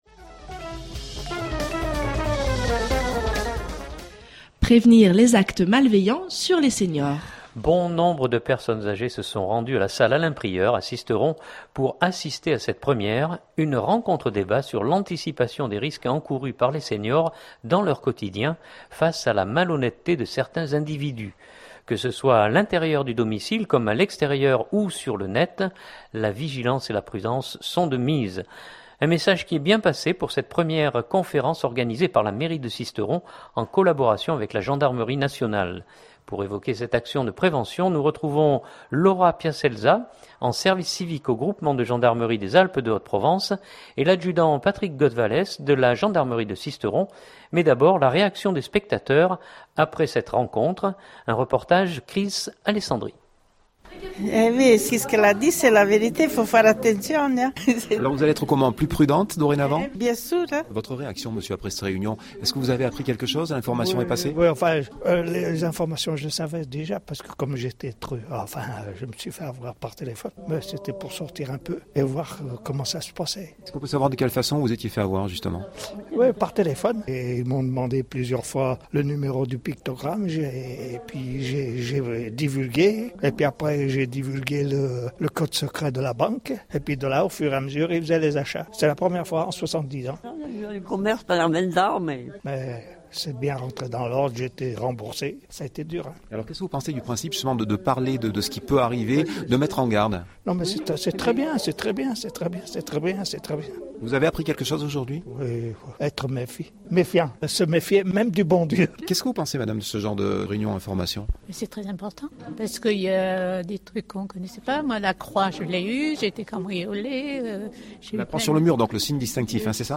mais d’abord la réaction des spectateurs après cette rencontre. Reportage